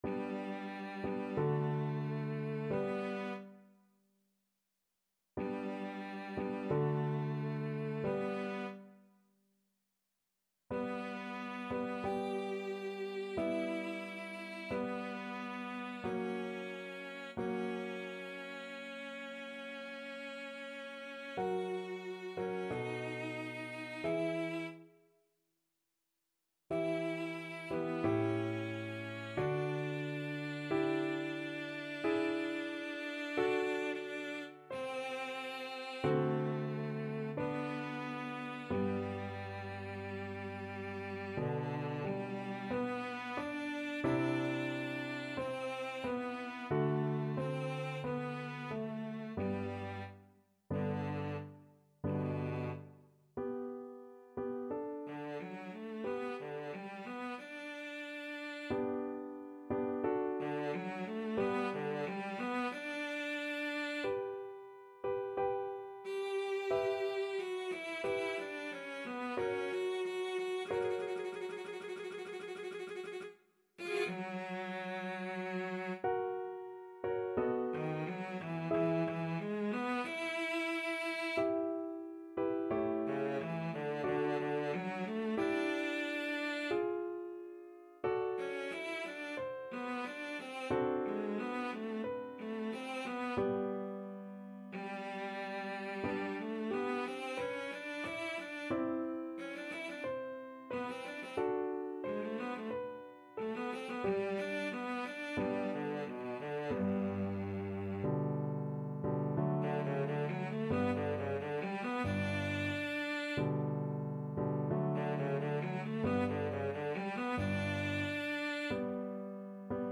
Cello version
Larghetto =c.45
4/4 (View more 4/4 Music)
Classical (View more Classical Cello Music)